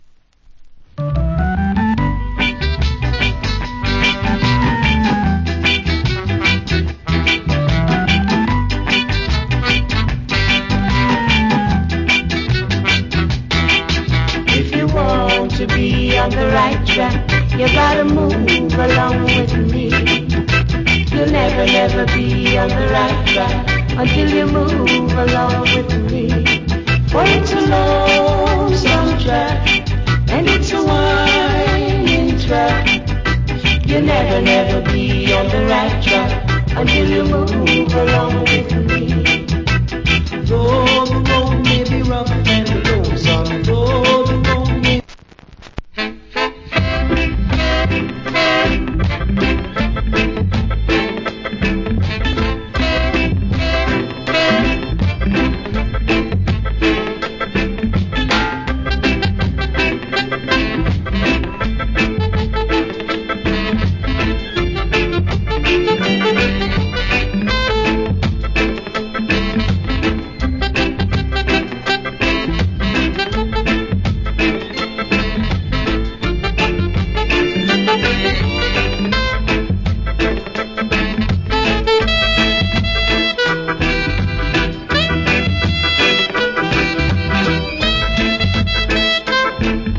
Great Early Reggae Vocal.